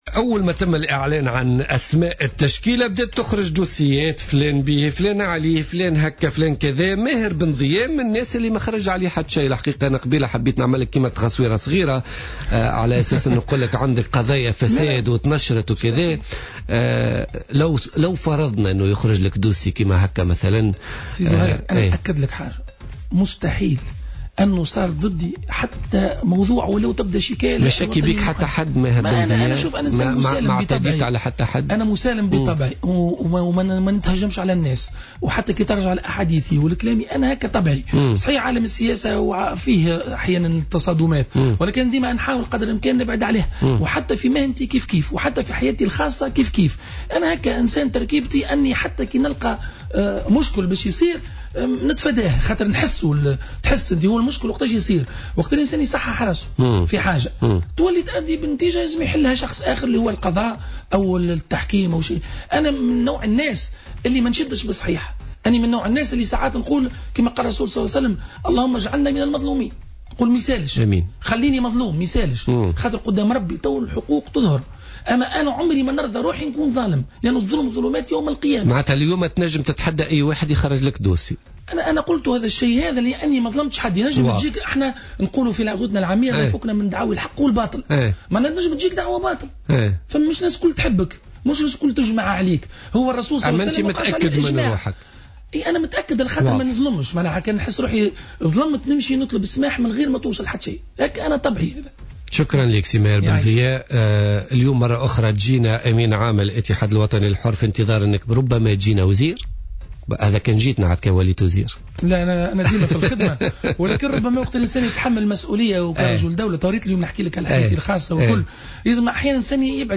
Invité sur le plateau de Jawhara Fm, Ben Dhia a affirmé qu’il ne fait l’objet d’aucune poursuite judiciaire.